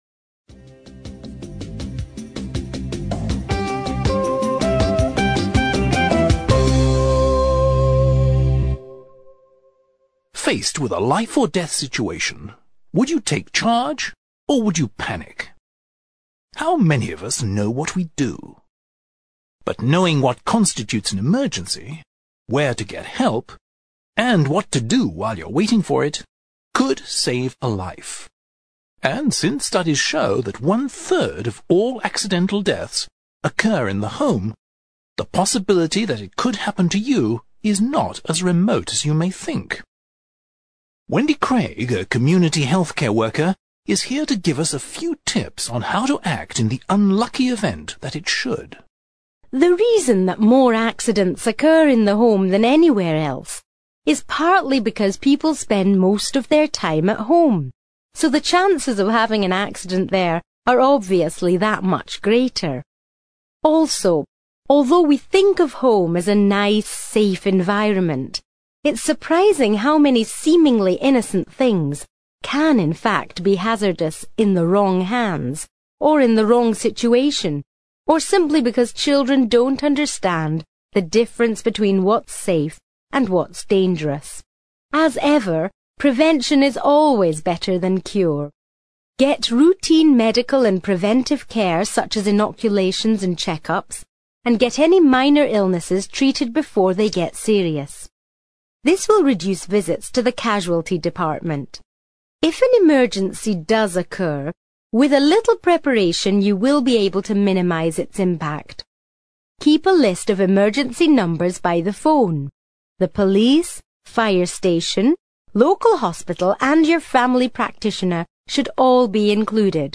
ACTIVITY 92: You will hear an extract from a radio programme in which a healthcare worker gives advice on how to act in an emergency.